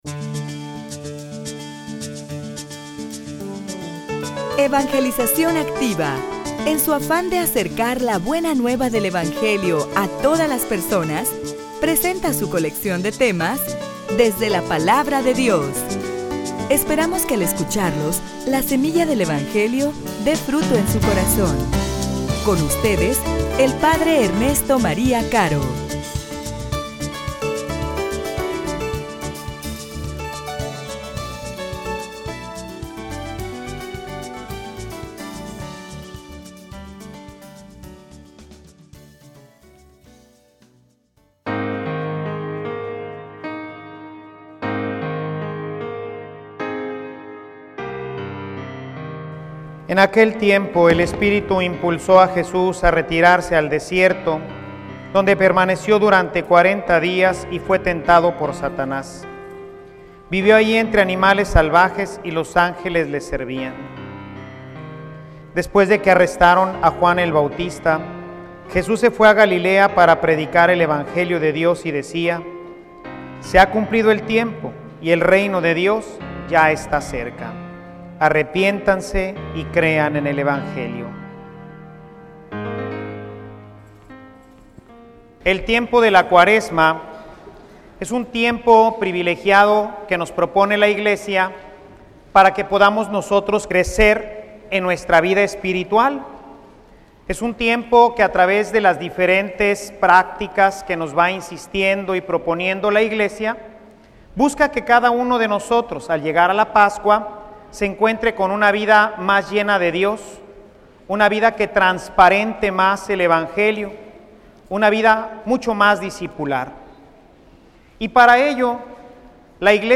homilia_El_ayuno_camino_hacia_la_victoria.mp3